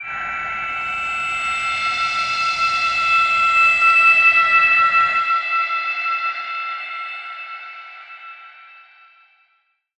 G_Crystal-E7-f.wav